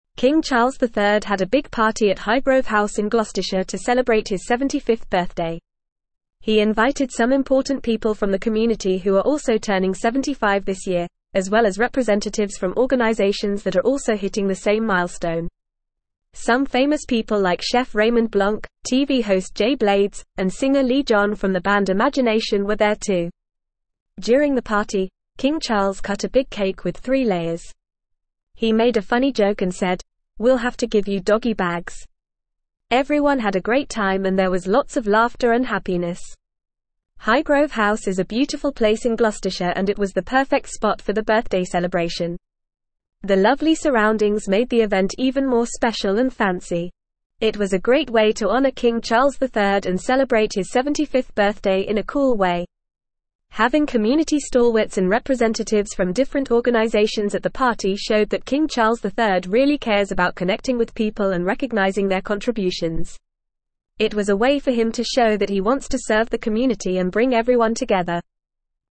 Fast
English-Newsroom-Upper-Intermediate-FAST-Reading-King-Charles-III-Celebrates-75th-Birthday-with-Joyful-Party.mp3